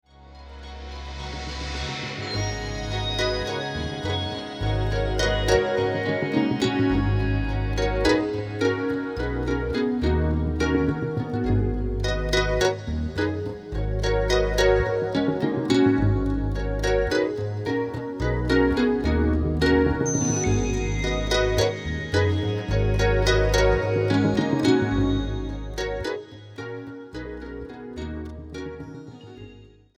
percussion
drums